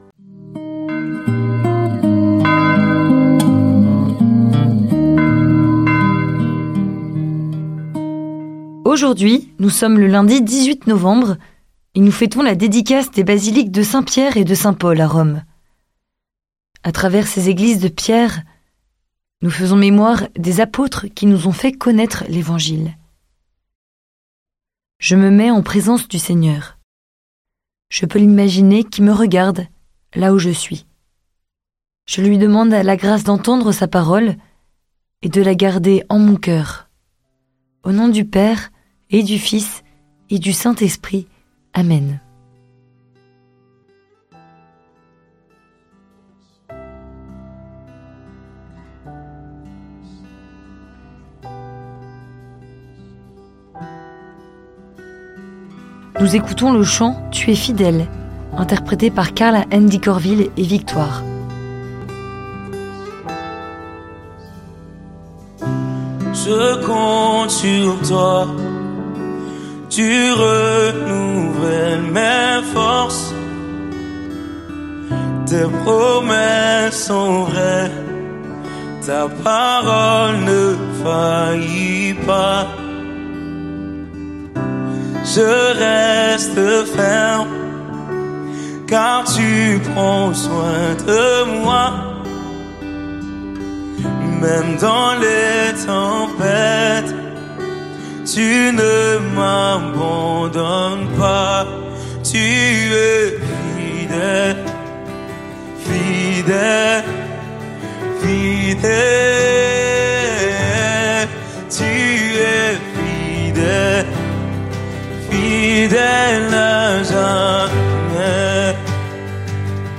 Musiques